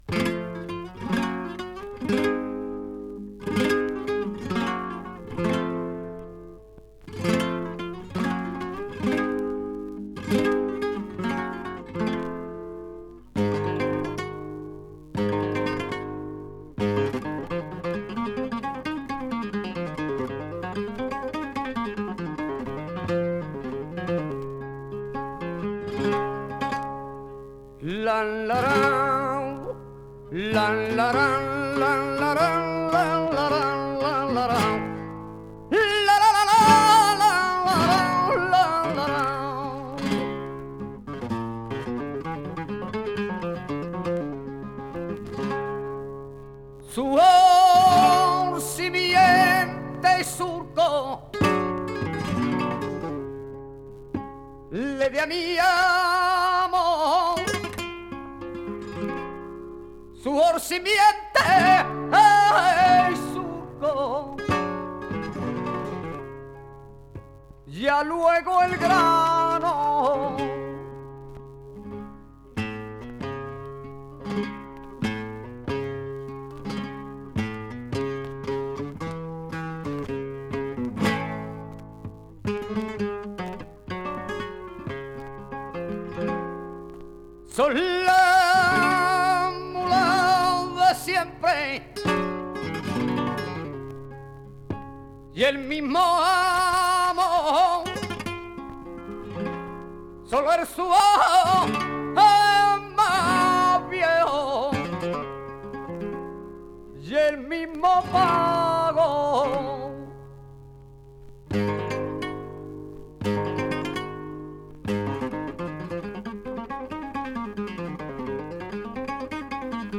chant
guitare